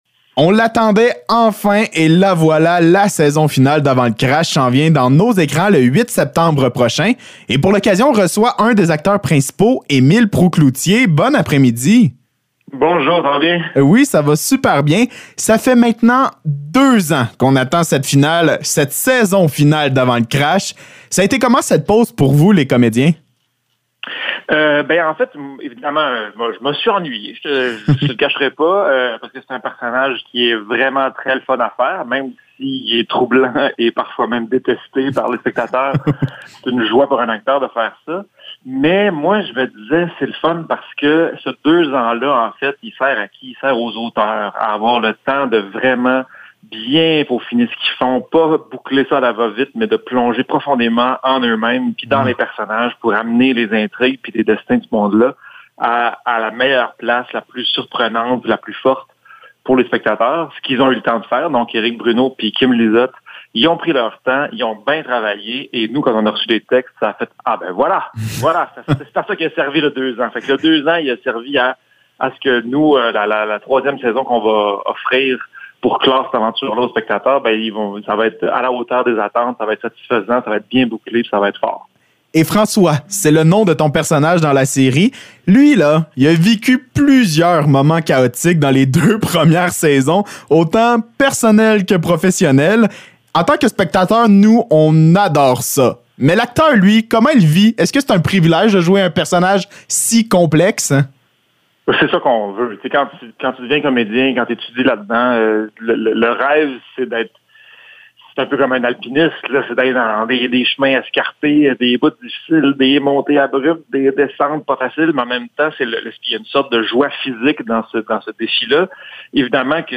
Entrevue avec Émile Proulx-Cloutier
ENTREVUE-EMILE-PROULX-CLOUTIER-AVANT-LE-CRASH.mp3